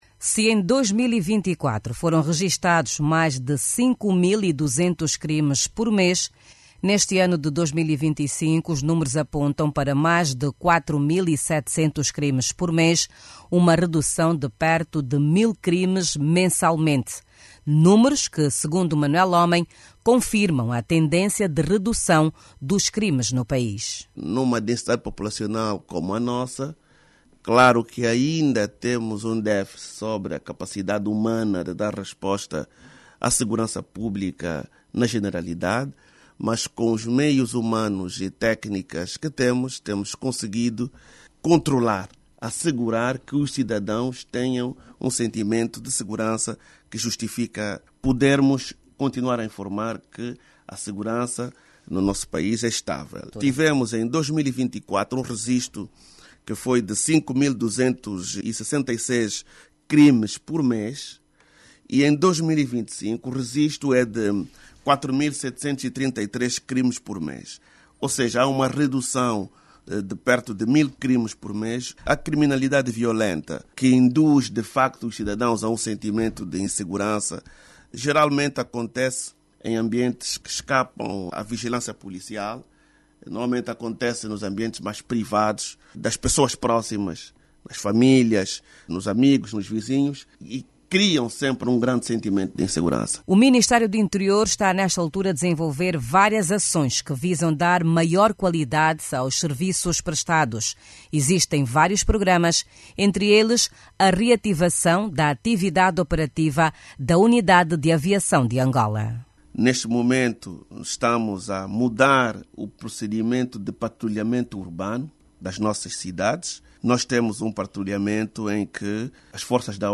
Em entrevista exclusiva à RNA, o governante reconheceu as dificuldades existentes, mas sublinhou que tem sido possível garantir a protecção dos cidadãos com os meios disponíveis.